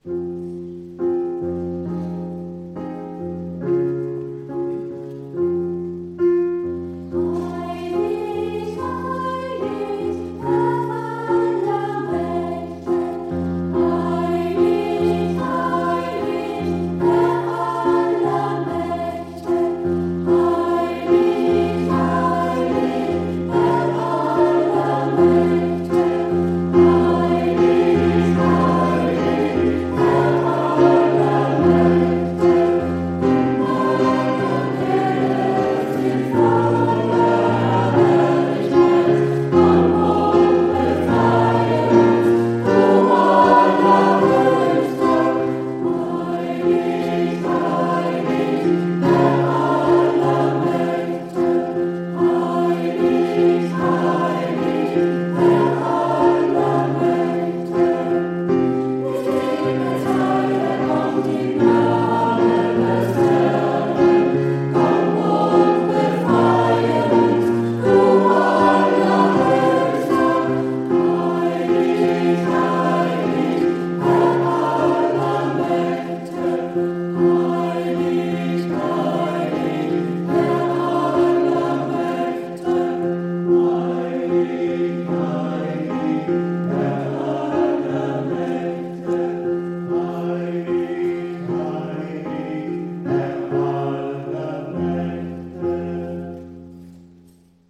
Wir feierten am 16.12.2018 den 3. Advent unter dem Motto "Zeit, Herberge zu geben".
Die Lieder aus dem Gottesdienst